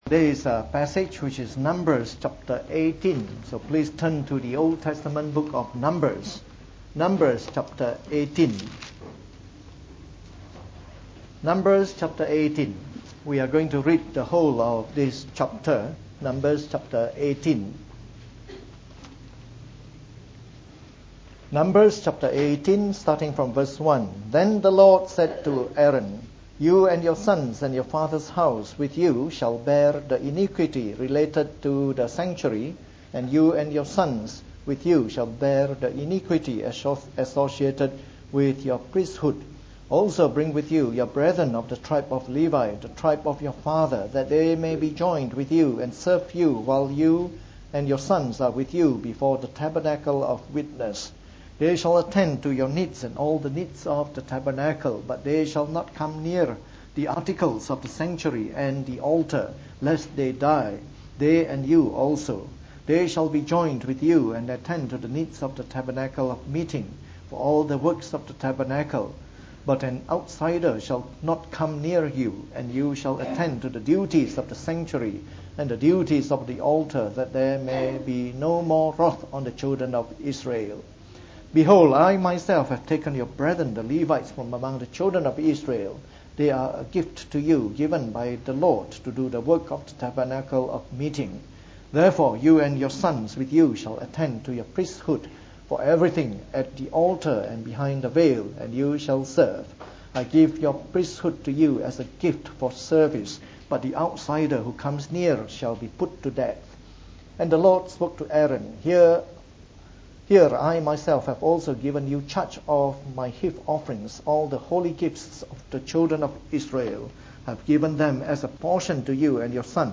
From our series on the “Book of Numbers” delivered in the Morning Service.